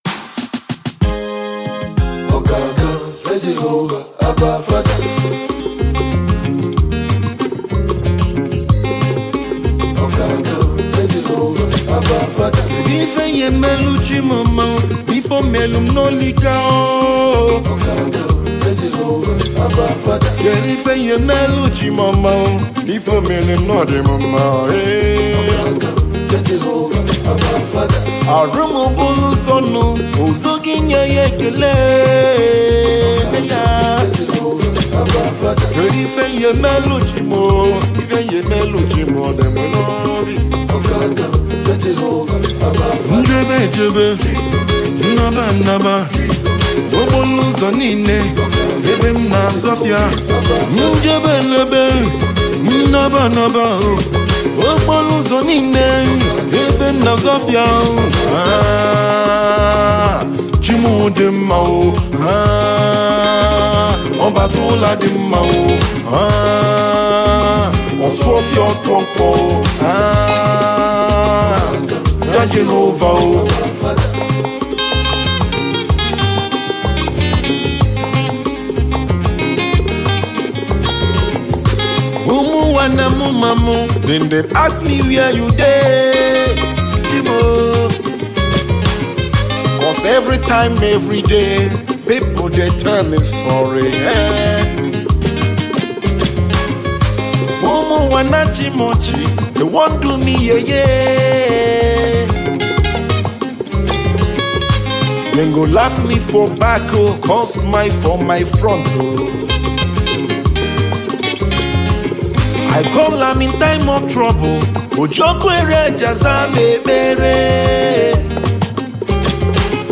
Gospel
music comes heavily blended in traditional African rhythms